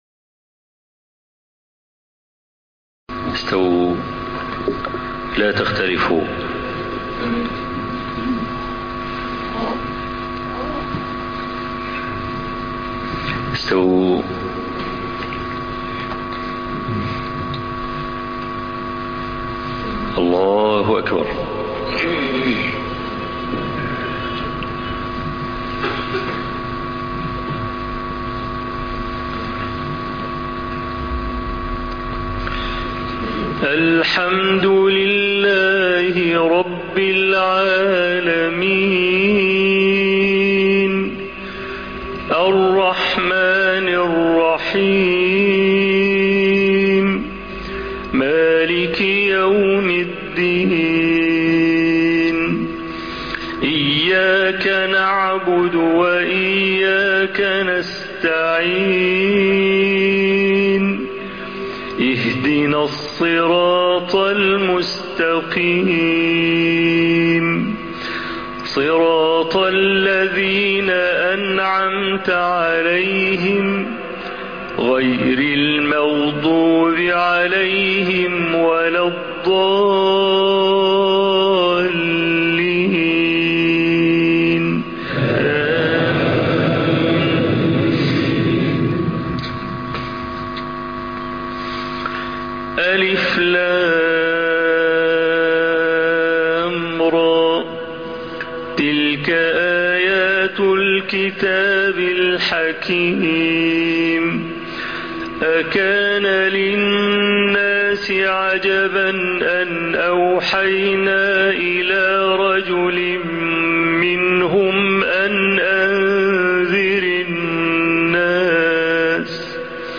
سورة يونس | بصوت الشيخ الحويني - الشيخ أبو إسحاق الحويني